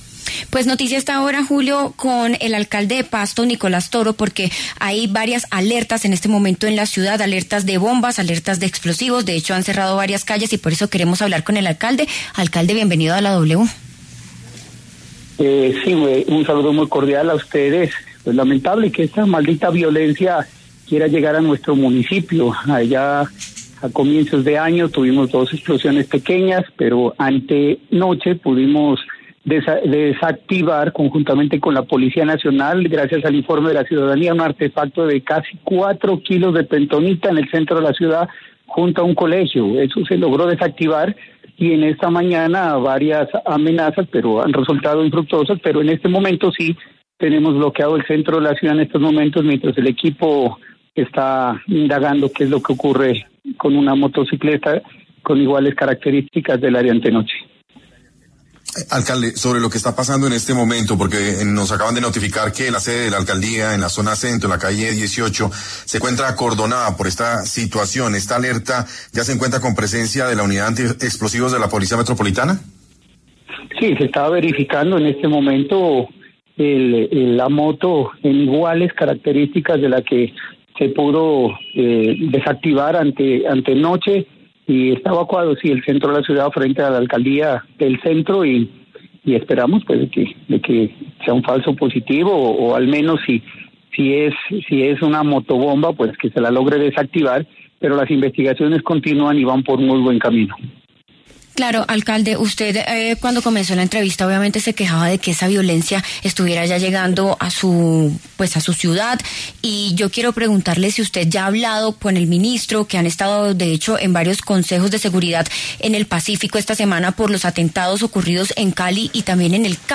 El alcalde Nicolás Toro Muñoz en diálogo con La W confirmó la situación que afronta la capital de Nariño después de las alertas por presencia de supuestos artefactos explosivos que hacen parte de una gran escala de hechos violentos registrados en todo el sur occidente del país.